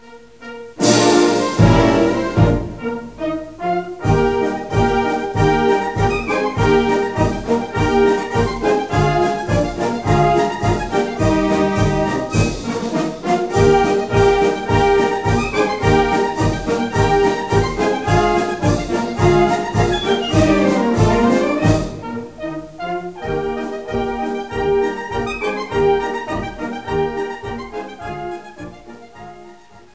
Aerophon (z.B. Blasintrument) (13/13)
Österreich-LexikonBlasmusik, Deutschmeister, Jurek, Wilhelm, Franzisko-josephinische Ära, Militärmusik